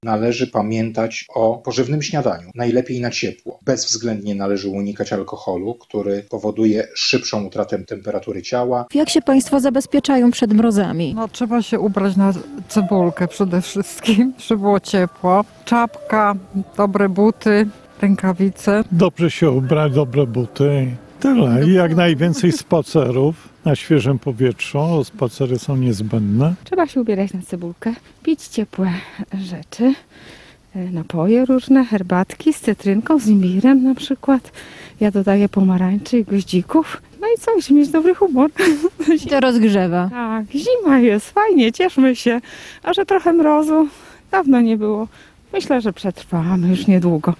Nadchodzą lodowate noce. Mieszkańcy mówią, jak sobie radzą [SONDA]
IMGW ostrzega, że mróz może sięgnąć nawet –23°C. O przygotowaniach do zimy i bezpieczeństwie mieszkańców opowiadają uczestnicy naszej sondy.